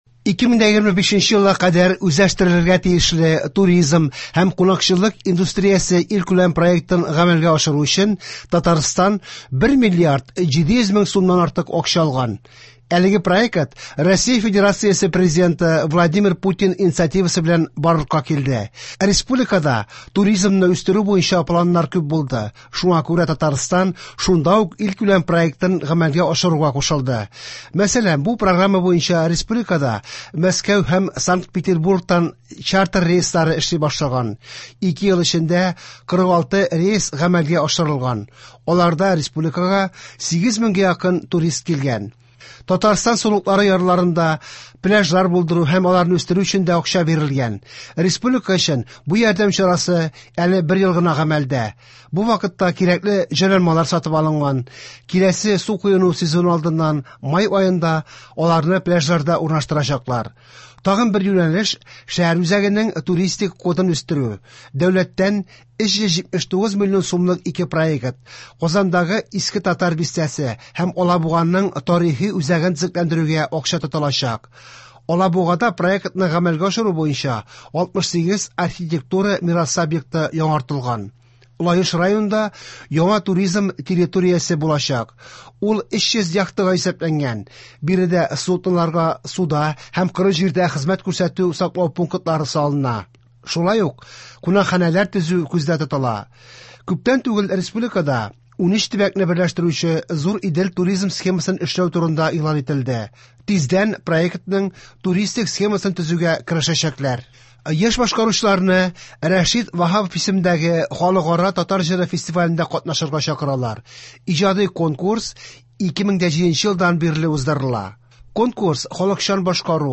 Яңалыклар (31.01.24)